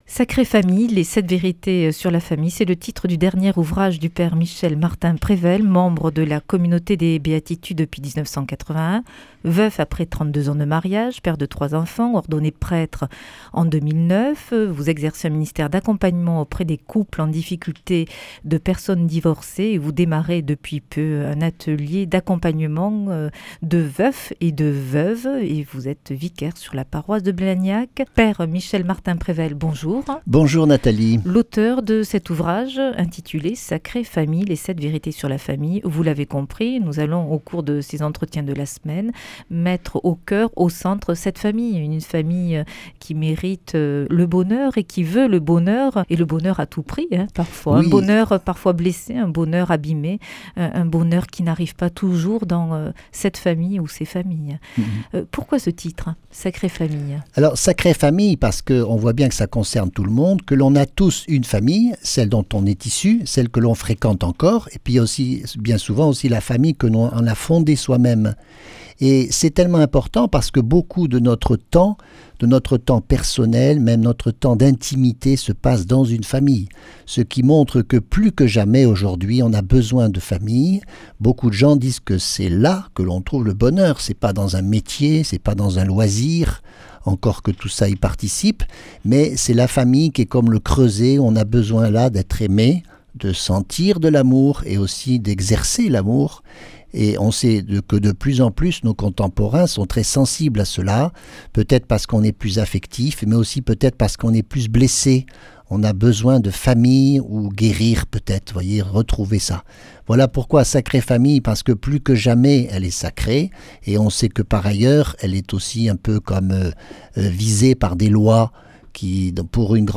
Invité de la semaine
Une émission présentée par